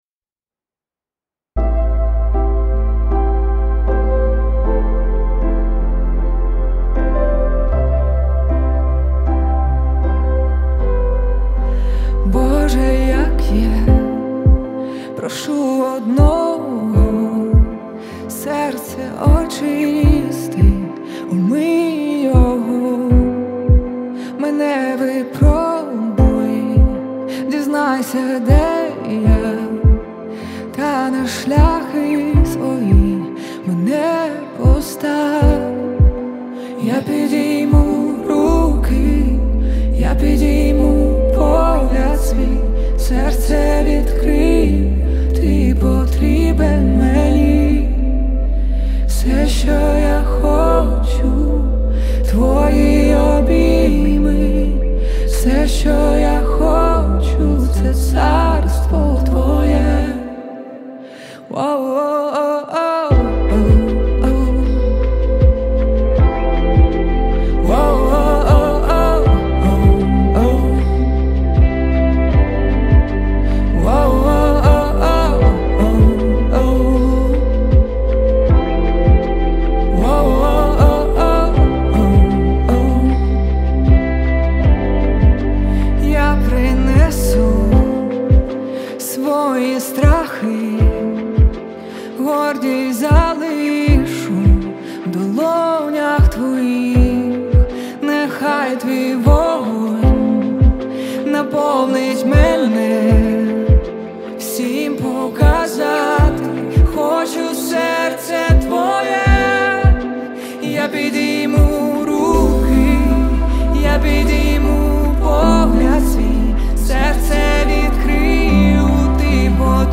1885 просмотров 1066 прослушиваний 77 скачиваний BPM: 77